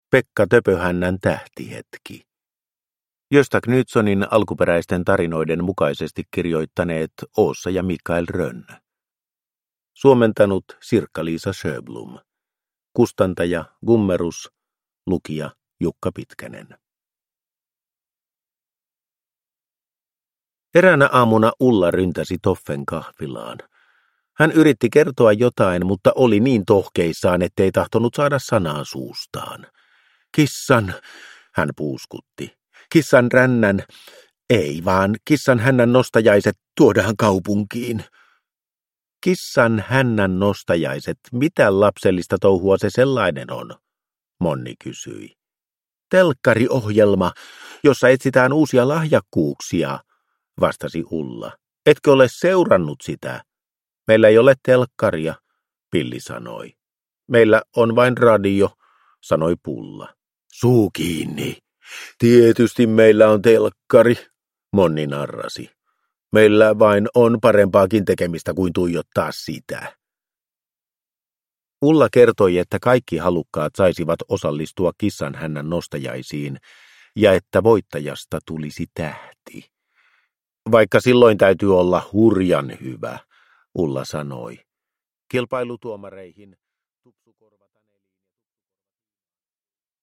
Pekka Töpöhännän tähtihetki – Ljudbok